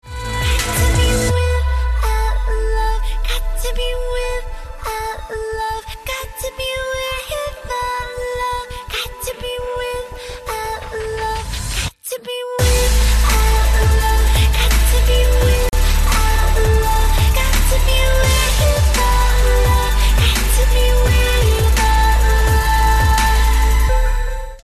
• Качество: 128, Stereo
Electronic
красивый женский вокал
Electropop